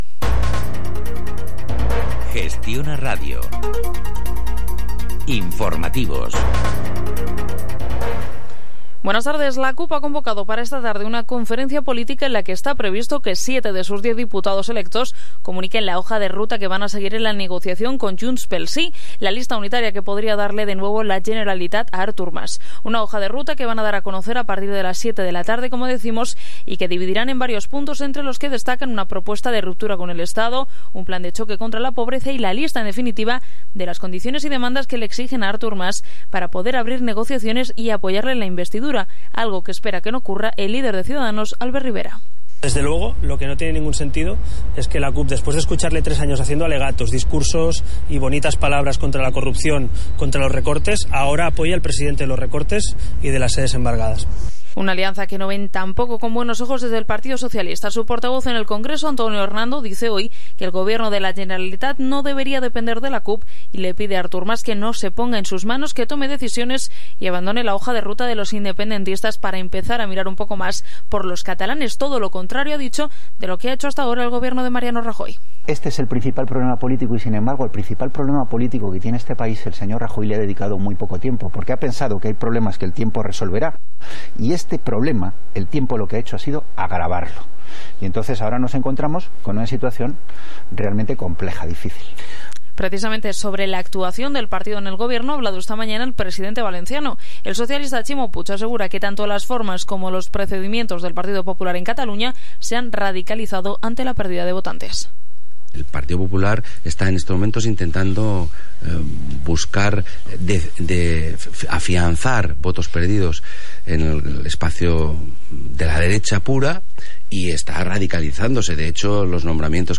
El programa de radio ‘Enfermedades Raras’ del 8 de septiembre de 2015